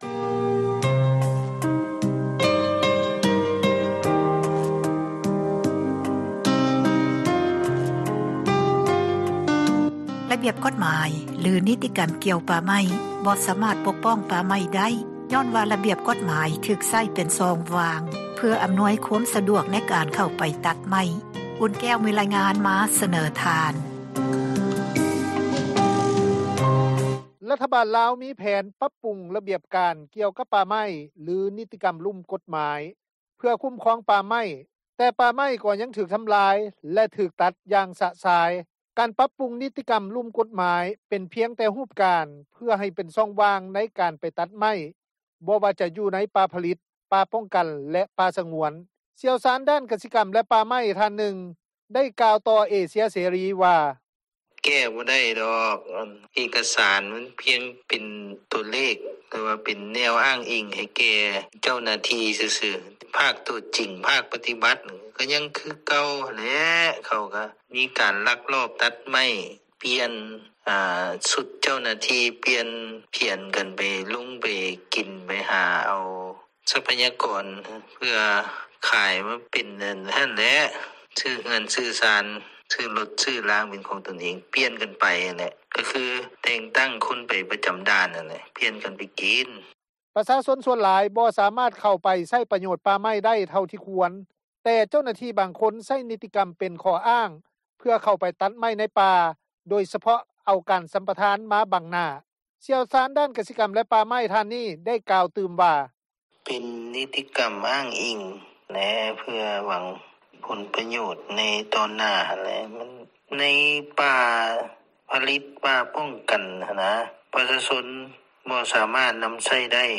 ຊ່ຽວຊານດ້ານກະສິກັມ ແລະ ປ່າໄມ້ ທ່ານນຶ່ງ ໄດ້ກ່າວຕໍ່ວິທຍຸ ເອເຊັຽເສຣີ ວ່າ:
ນັກທຸຣະກິຈ ຢູ່ພາກໃຕ້ ທ່ານນຶ່ງ ໄດ້ໃຫ້ສັມພາດຕໍ່ວິທຍຸ ເອເຊັຽເສຣີ ວ່າ: